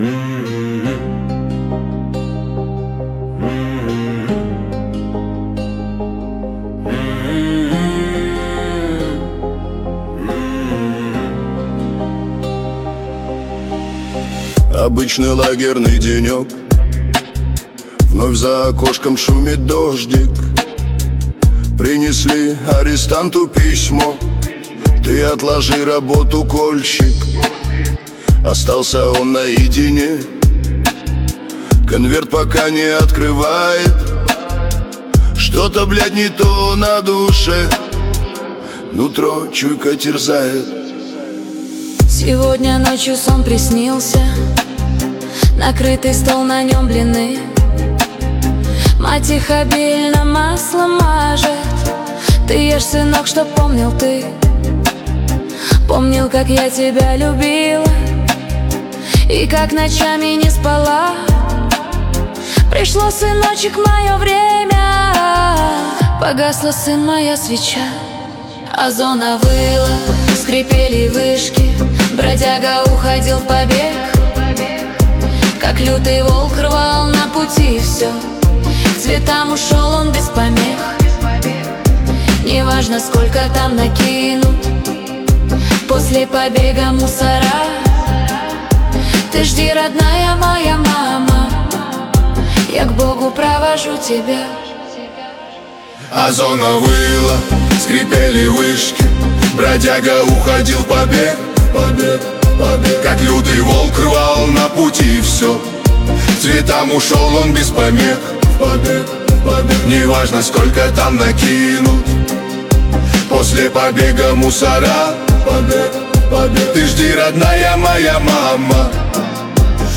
Качество: 320 kbps, stereo
Guitar кавер